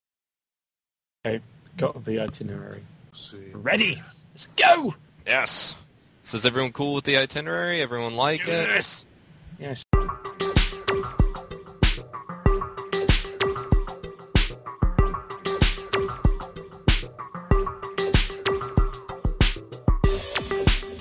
There’s a lot of laughter — and a lot of personal discussion.